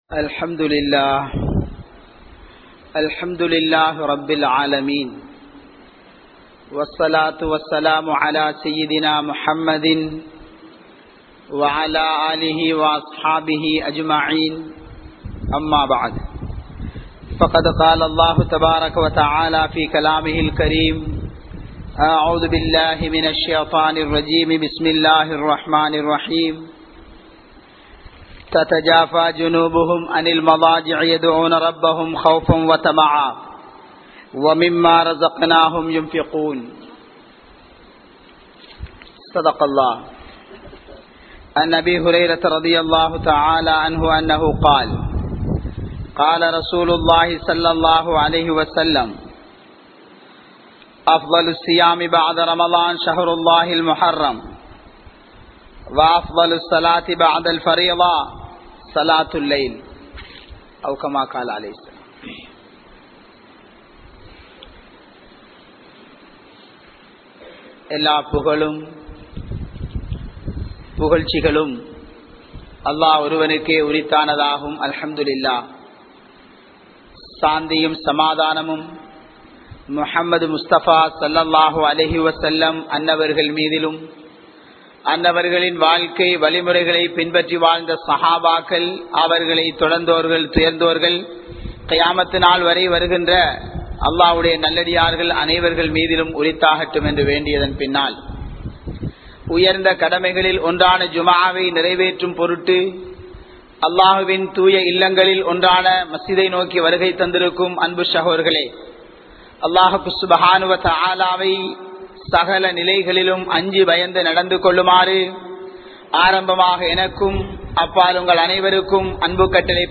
Iravai Iravaaha Kalikka Vilaaiya? (இரவை இரவாக கழிக்கவில்லையா?) | Audio Bayans | All Ceylon Muslim Youth Community | Addalaichenai